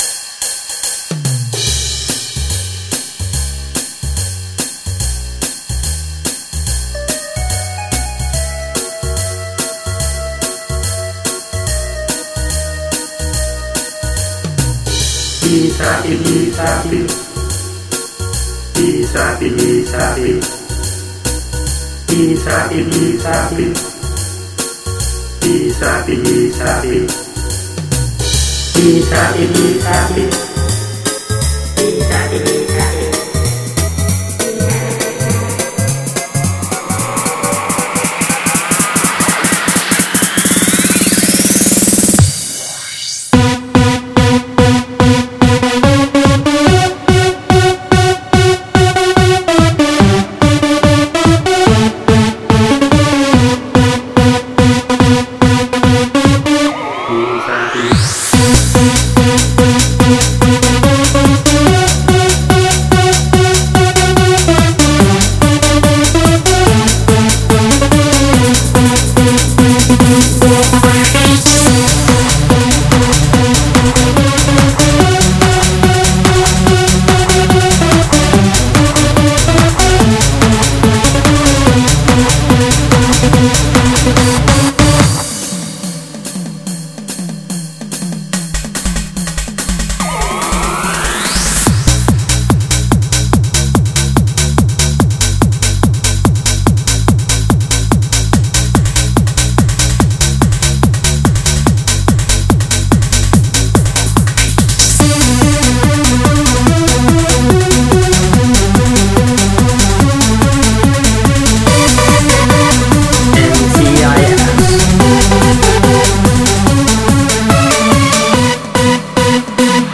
dance/electronic
Trance